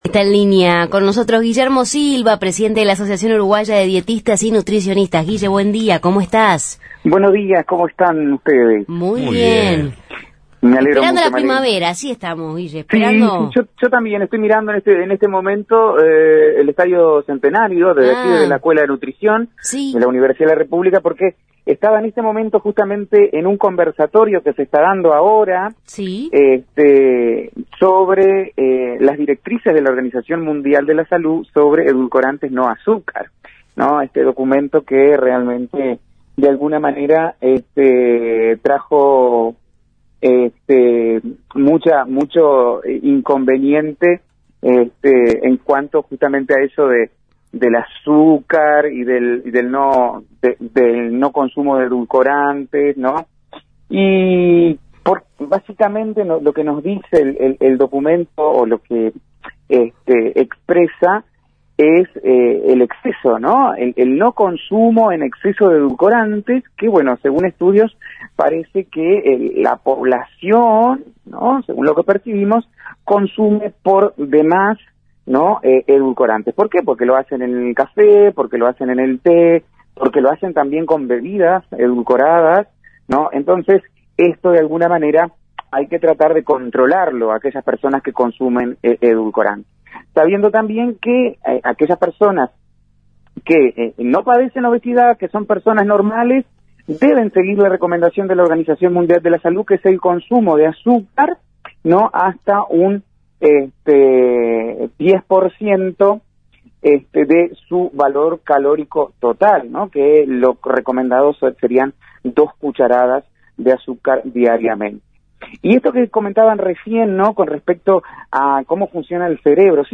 Columna de nutrición en Justos y pecadores